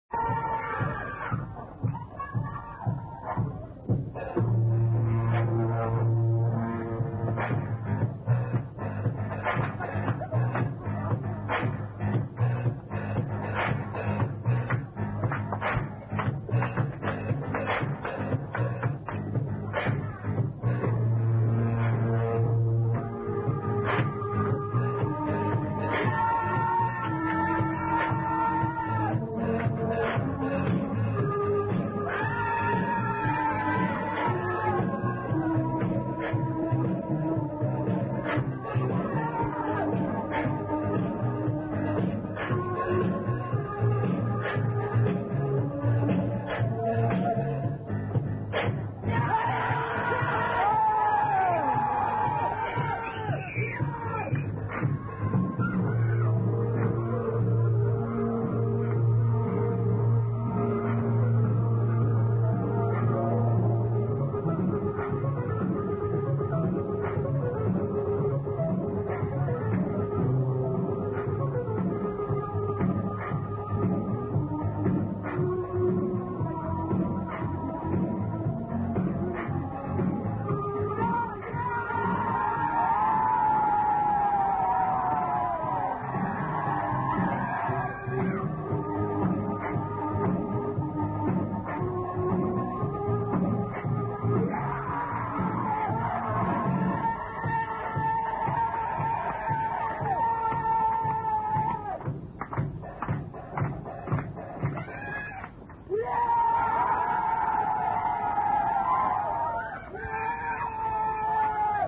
9.05.91.- France, Dunkerque